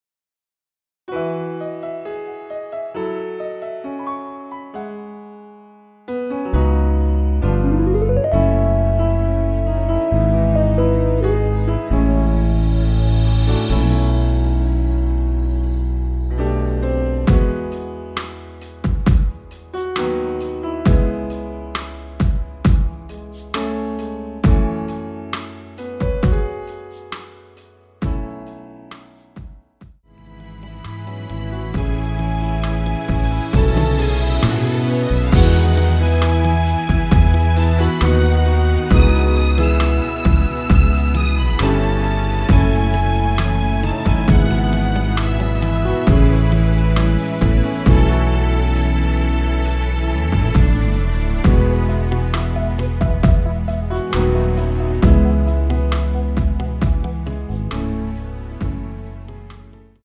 반키(-1)내린 MR 입니다.
엔딩이 페이드 아웃이라 엔딩을 만들어 놓았습니다.
앞부분30초, 뒷부분30초씩 편집해서 올려 드리고 있습니다.
중간에 음이 끈어지고 다시 나오는 이유는